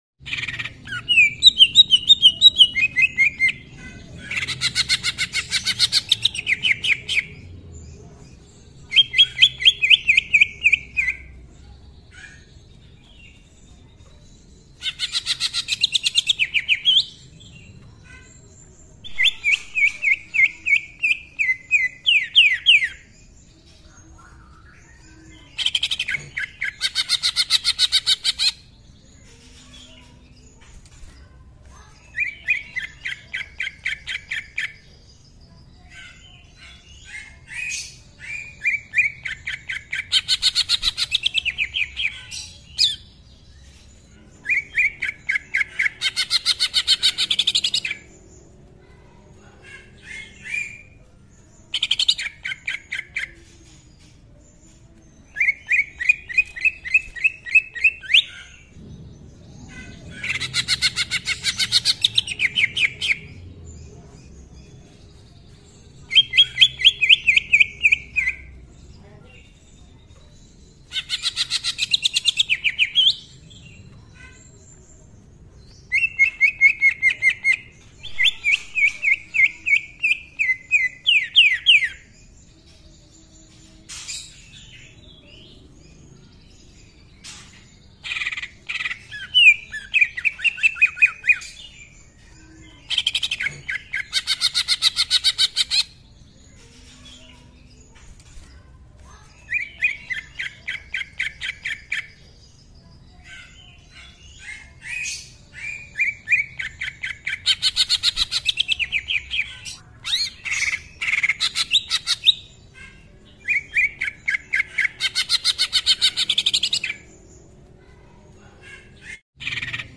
Suara Cucak Ijo Gacor Full Isian Agar Cepat Menirukan
Kategori: Suara burung
Cucak Ijo Gacor Ngentrok Full Isian, Nembak Rapet dengan Suara Kasar Isian Mewah.
suara-cucak-ijo-gacor-full-isian-agar-cepat-menirukan-id-www_tiengdong_com.mp3